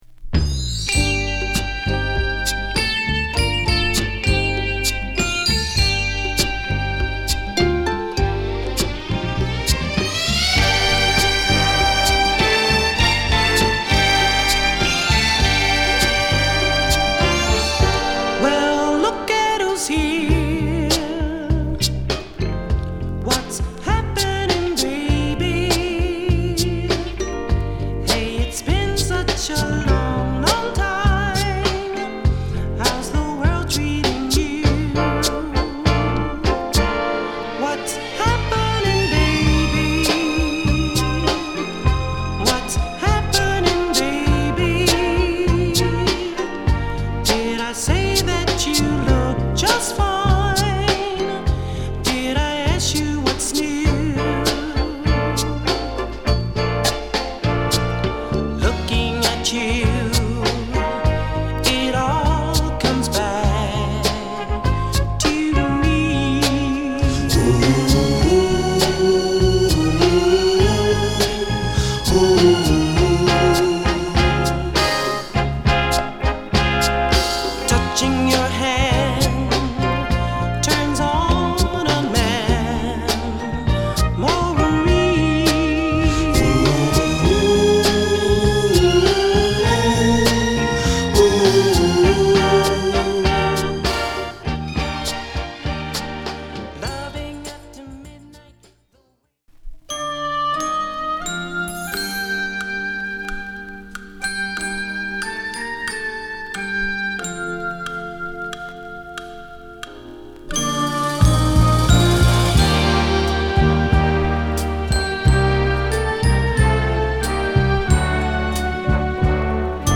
流麗でキャッチーなサウンドとヴォーカルWorkが見事融合。
あと、抜けるホーンなどもアリマス。